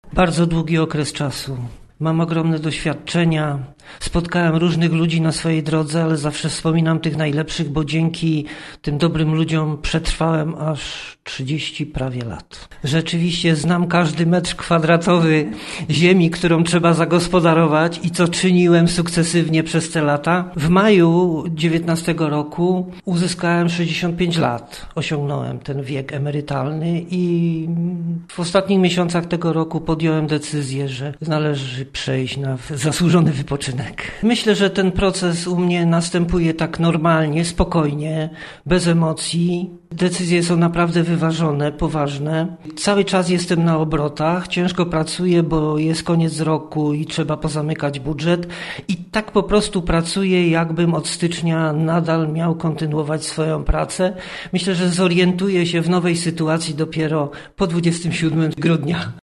Rozmowy z wójtem Wierzchlasa posłuchasztutaj.